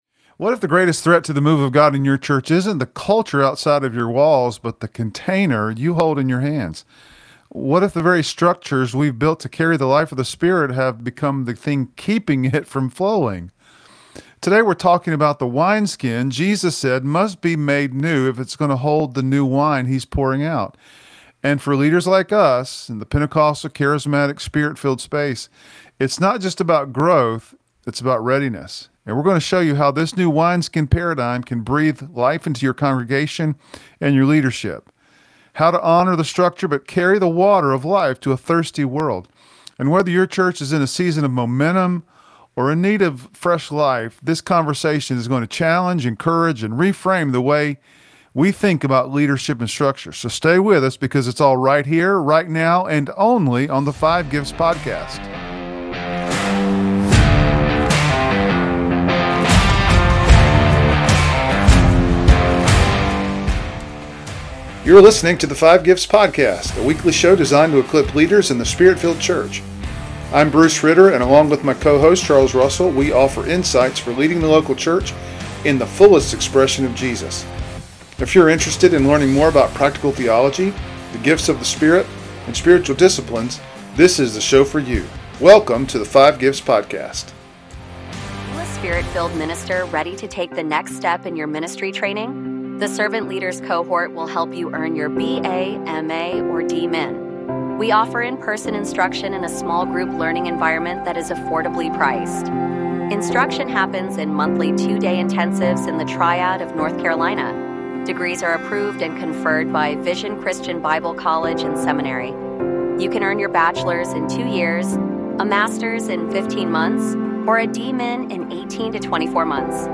Our hosts—operating in Apostolic, Prophetic, and Teaching gifts—share biblical insights, Spirit-led illustrations, and practical strategies to help leaders prepare for fresh outpouring by becoming flexible, Spirit-ready wineski